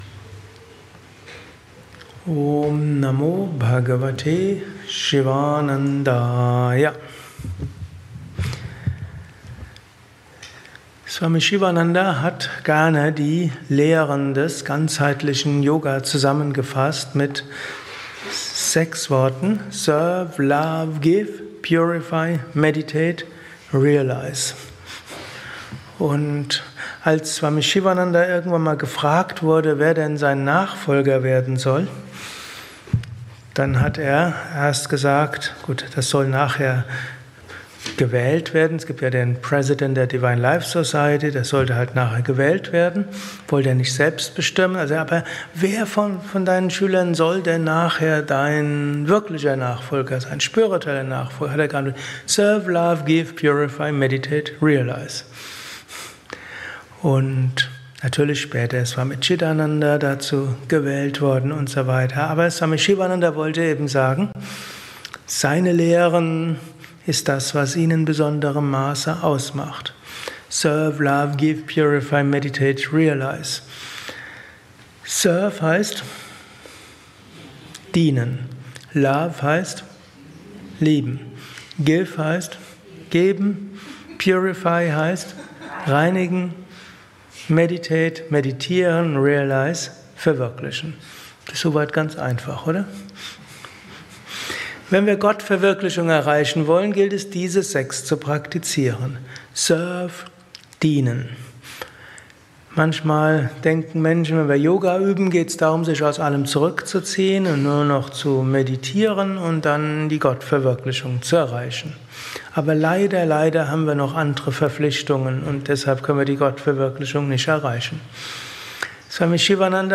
eine Aufnahme während eines Satsangs gehalten nach einer